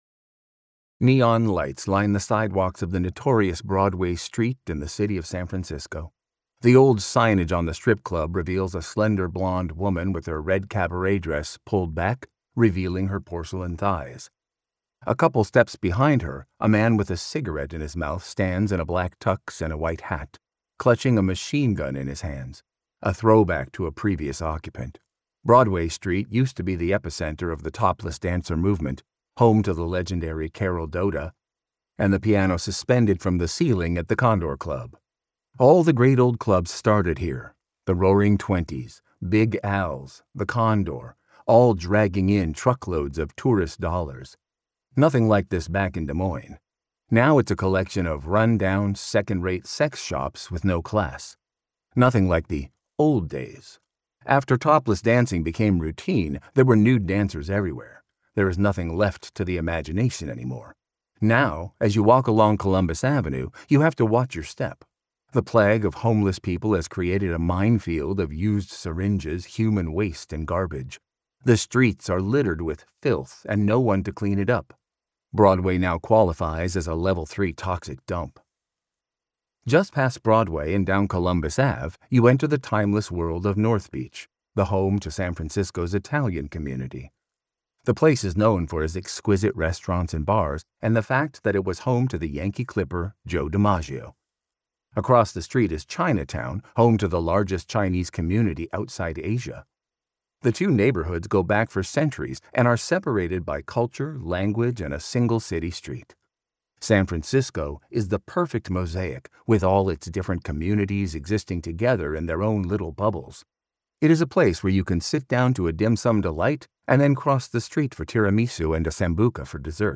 • Audiobook • 5 hrs, 34 mins